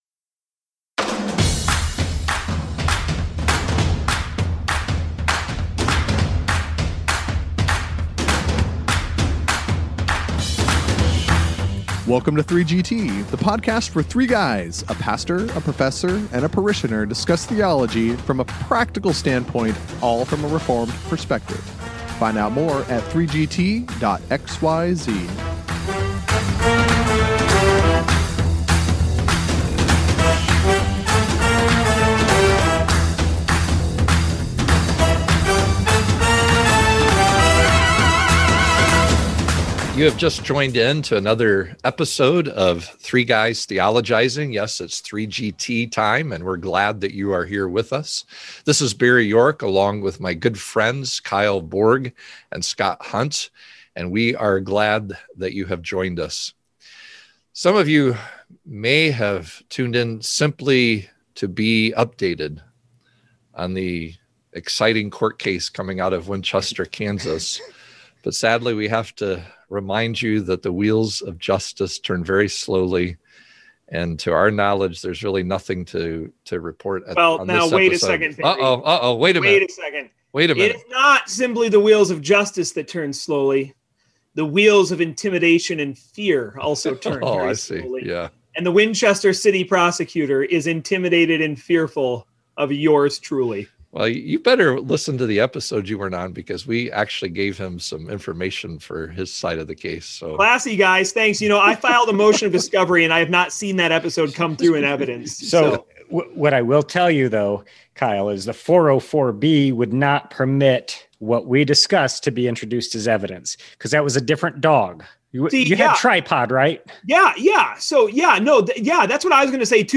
Join the guys for another fascinating discussion on 3GT!